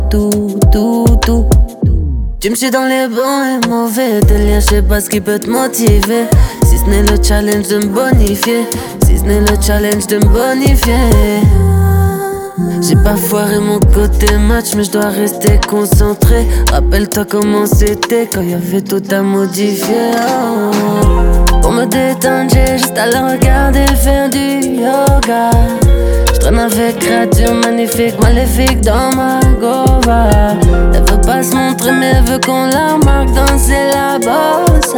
Жанр: Африканская музыка / Поп